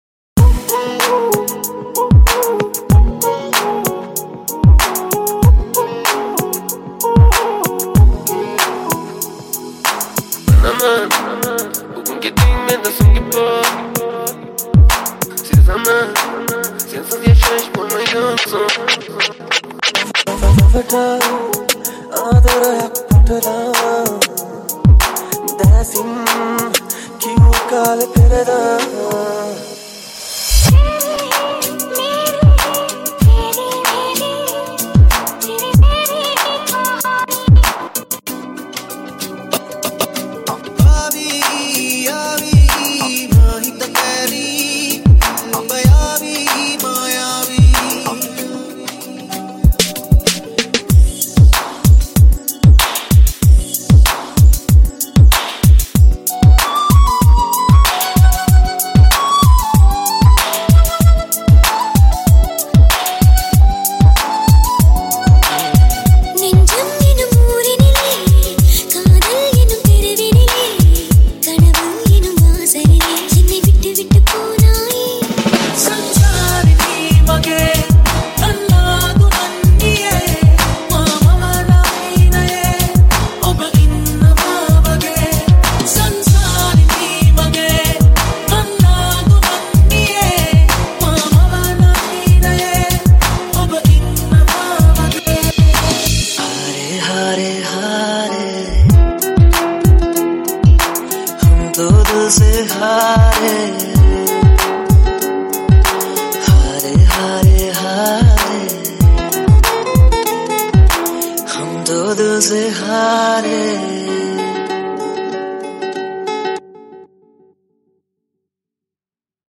High quality Sri Lankan remix MP3 (2).